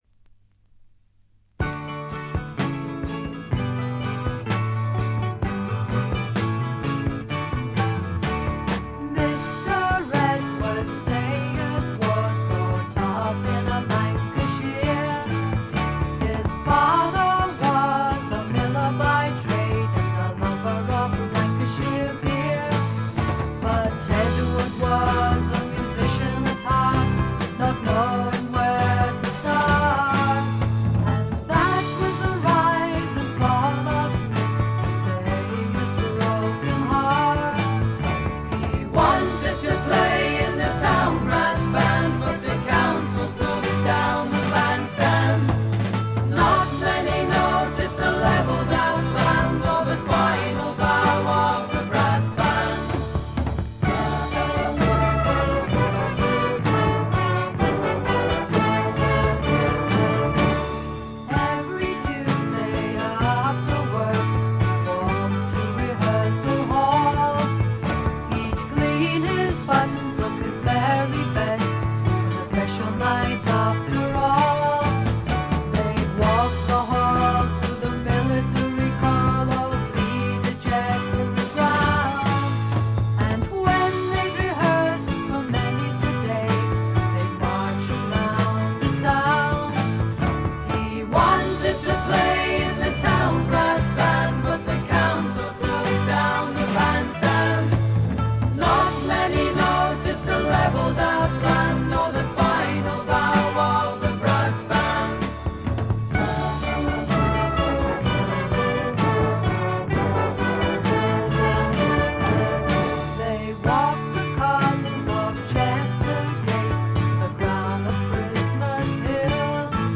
他は軽微なチリプチが少々。
試聴曲は現品からの取り込み音源です。